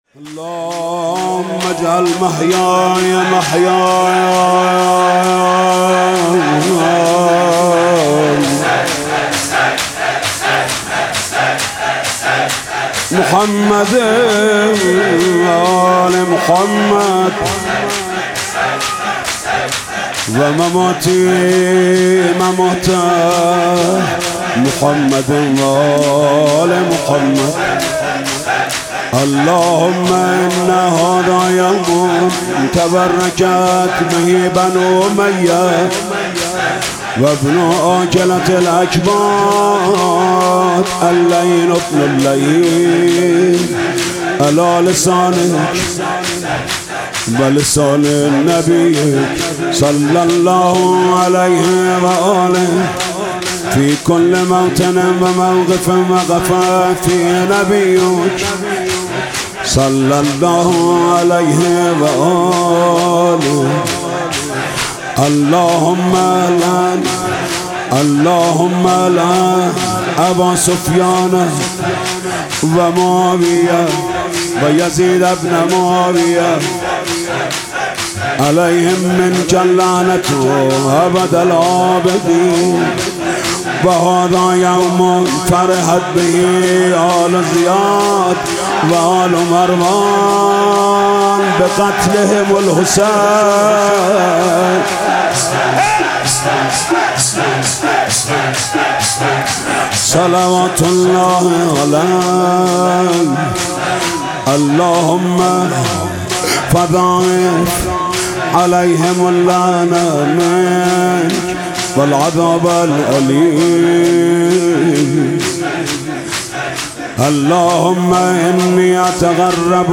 گزارش صوتی شب بیست و سوم ماه رمضان در هیئت رایة العباس(ع)
بخش اول - مناجات ( سلام ای تکیه گاه امّت )
نوحه
شور